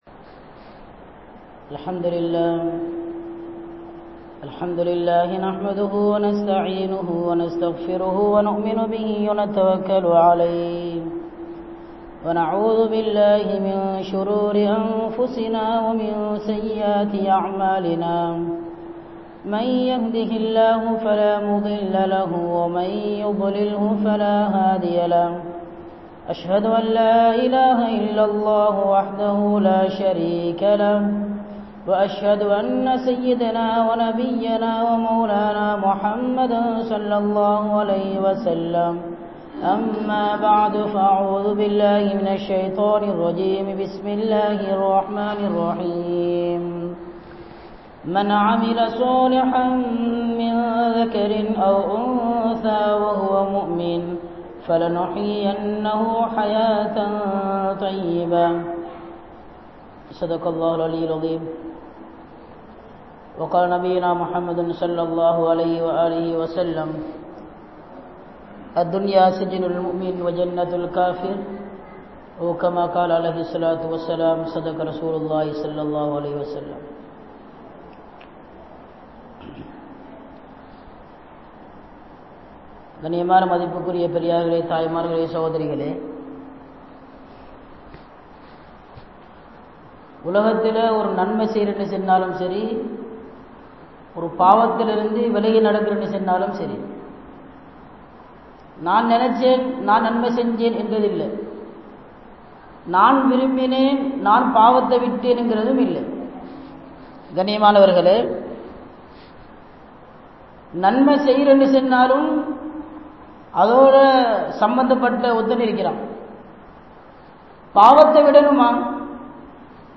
Islam Koorum Pengal (இஸ்லாம் கூறும் பெண்கள்) | Audio Bayans | All Ceylon Muslim Youth Community | Addalaichenai